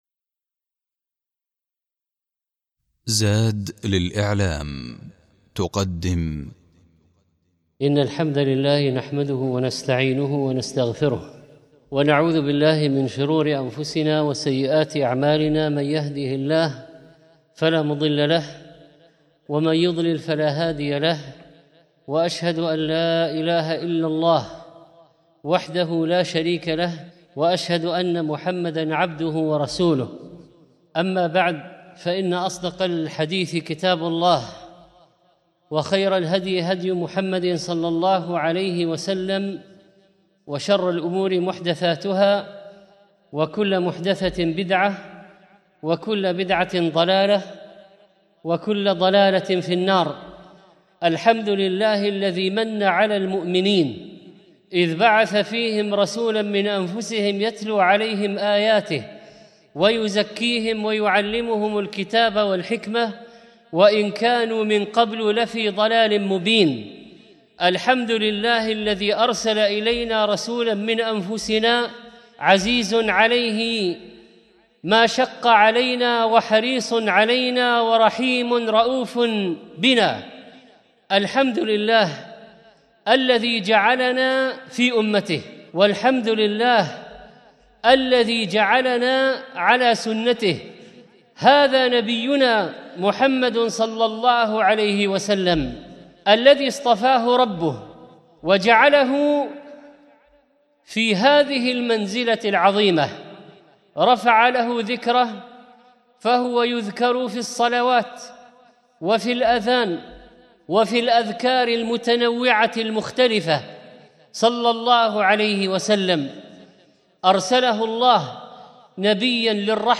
الخطبة الأولى
الخطبة الثانية